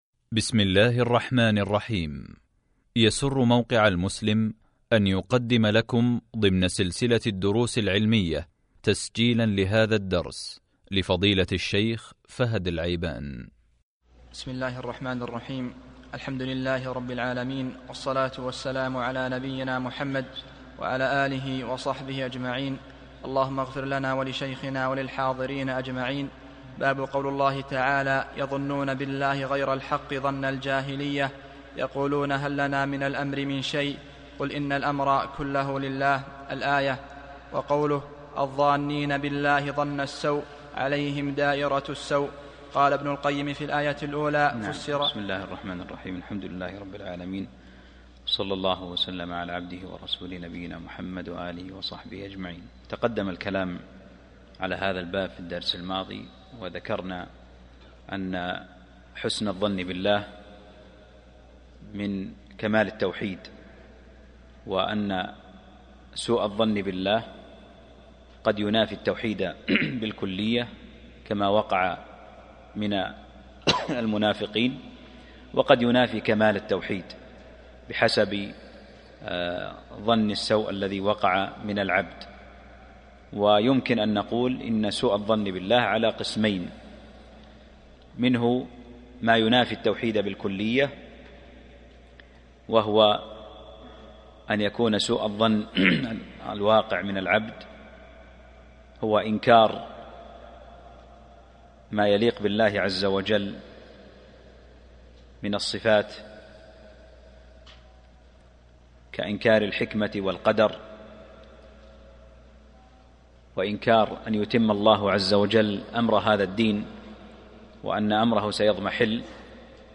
الدرس (50) من شرح كتاب التوحيد | موقع المسلم